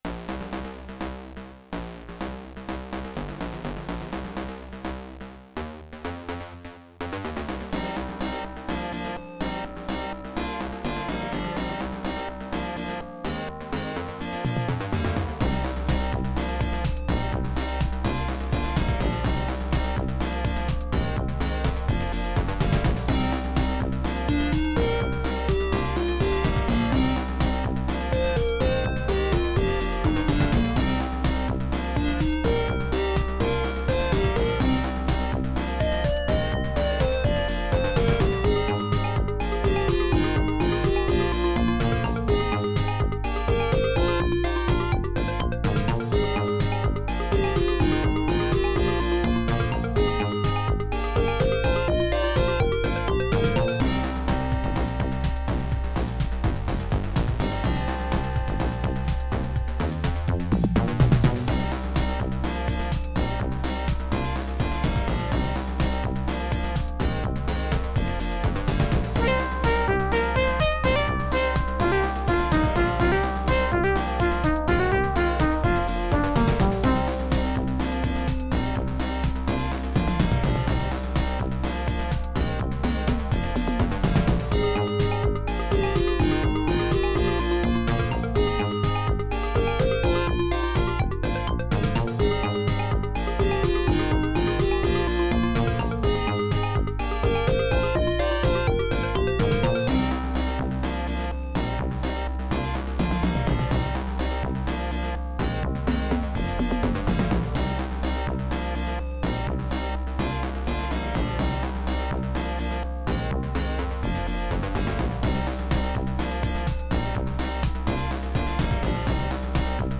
Music: midi
Creative SoundBlaster 1.5 ct1320(adlib driver)
* Some records contain clicks.